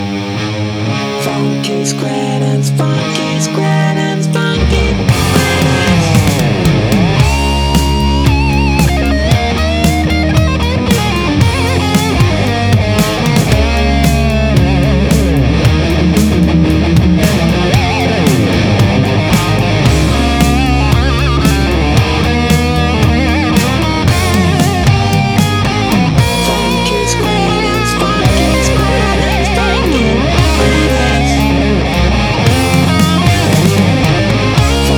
Жанр: Электроника / Рок